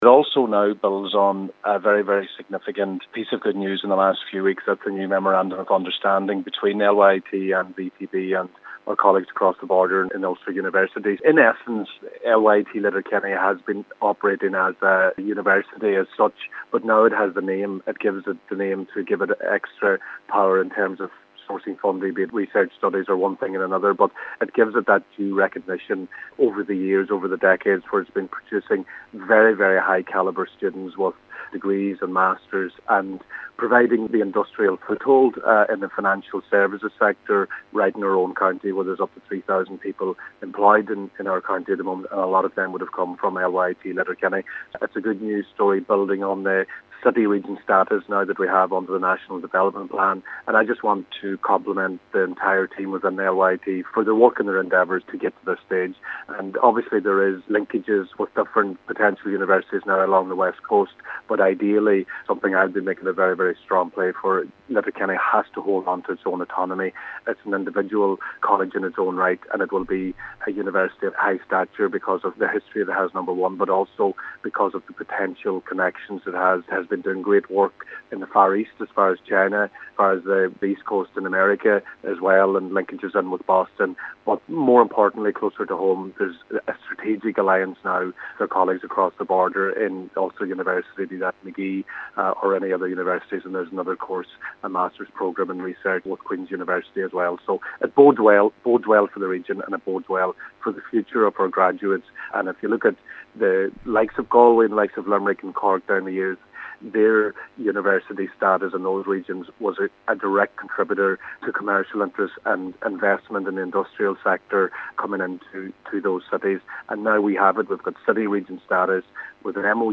Government Chief Whip Minister Joe McHugh says it is a welcome announcement building on the designation of Letterkenny, Derry and Strabane as a city region and the signing of the Memorandum of Understanding in recent weeks: